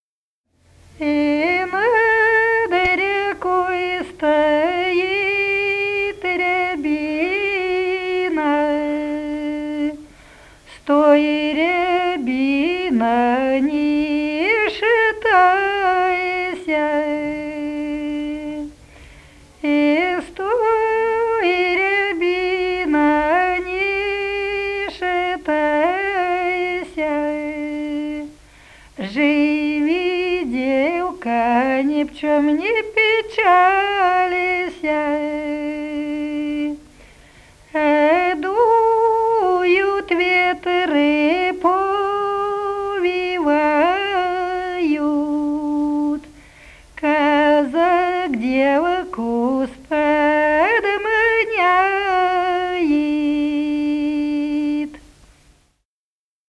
Народные песни Смоленской области
«И над рекой стоит рябина» (лирическая).